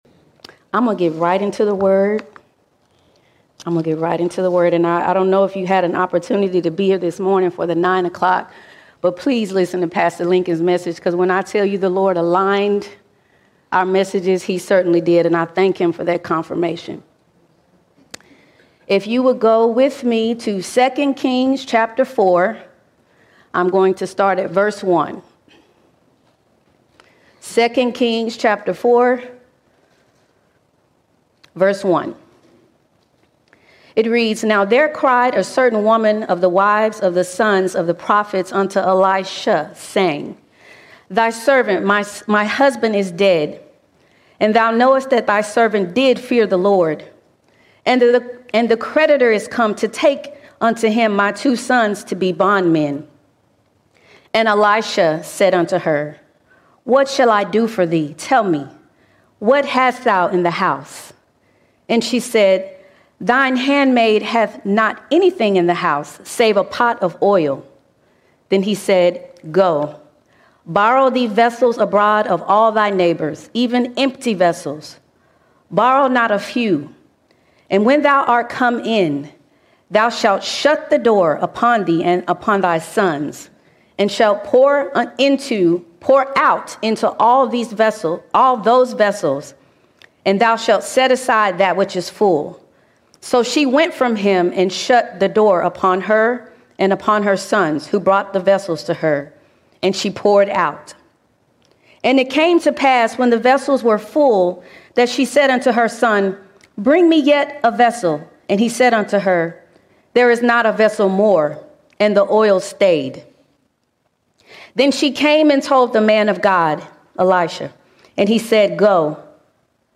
3 March 2025 Series: Sunday Sermons All Sermons Empty Vessel Empty Vessel God can use anything for His glory - especially an empty vessel surrendered to Him!